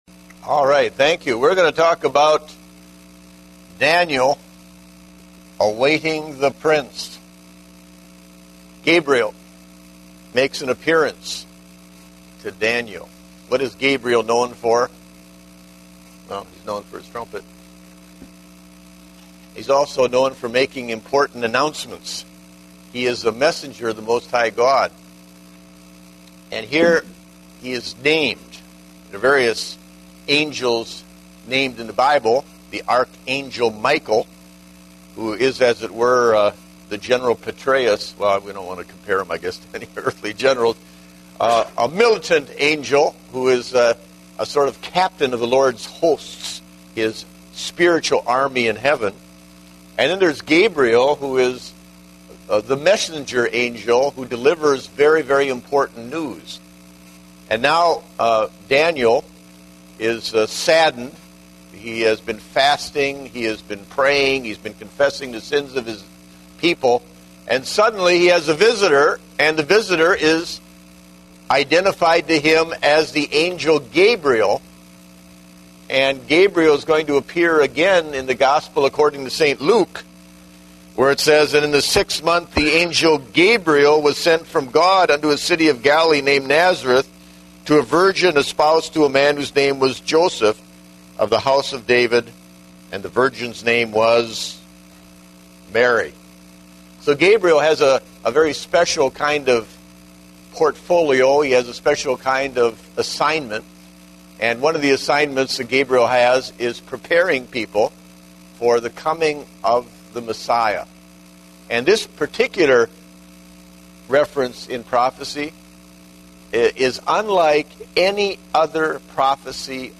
Date: December 19, 2010 (Adult Sunday School)